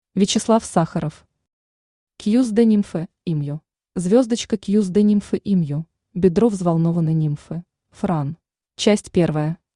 Аудиокнига Cuisse de Nymphe emue | Библиотека аудиокниг
Aудиокнига Cuisse de Nymphe emue Автор Вячеслав Валерьевич Сахаров Читает аудиокнигу Авточтец ЛитРес.